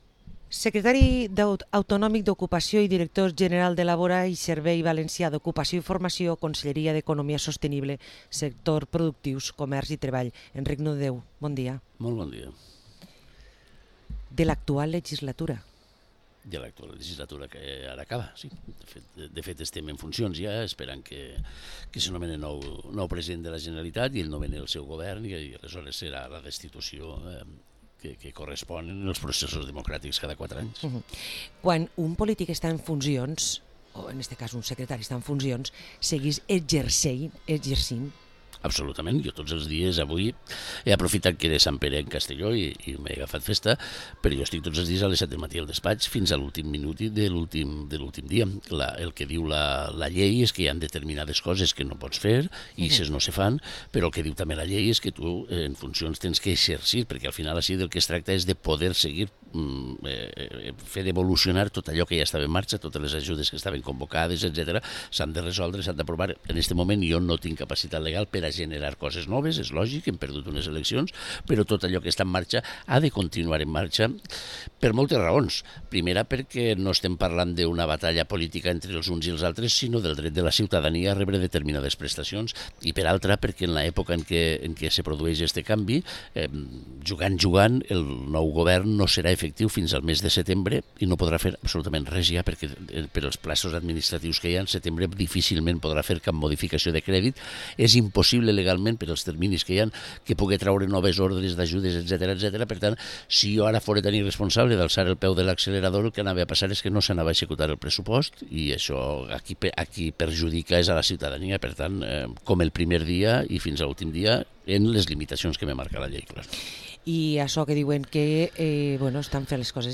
Entrevista al secretari autonòmic d’Ocupació i director general de LABORA, Enric Nomdedéu